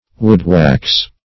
Wood-wash \Wood"-wash`\, Wood-wax \Wood"-wax`\, Wood-waxen